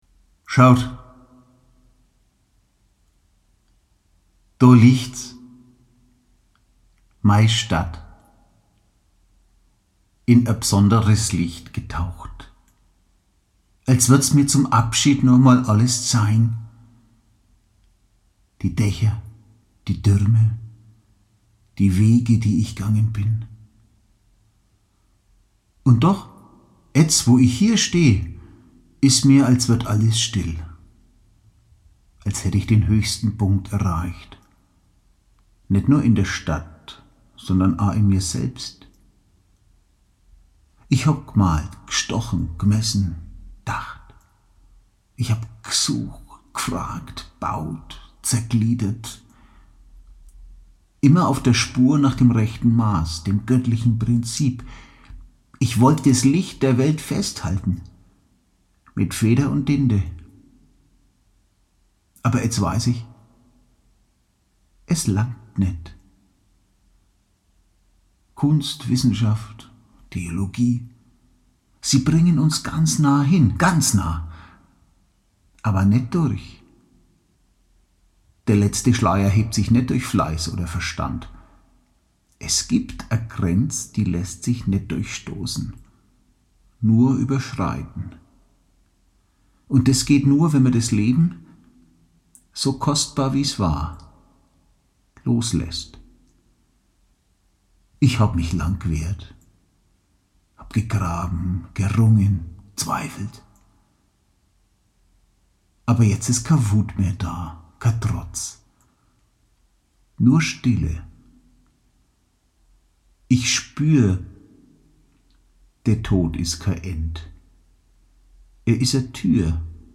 Stille. Ein paar Sekunden lang ist es einfach still.